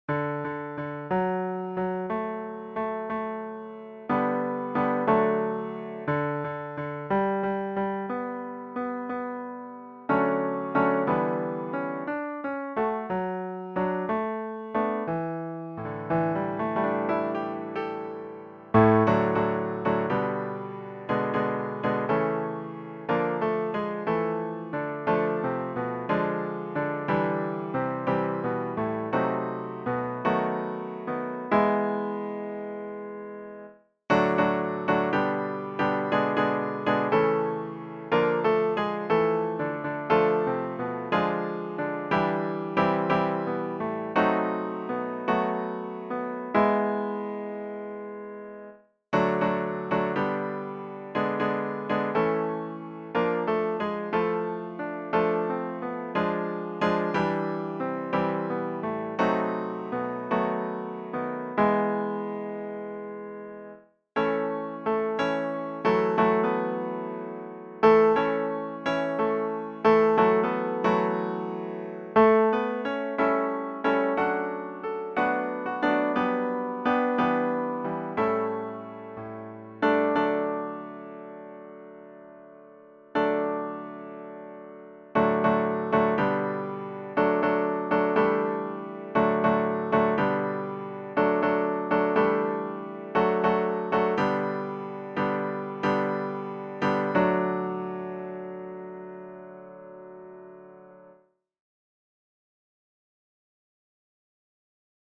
pour Ténor solo ad lib. & SSAATTBB a cappella texte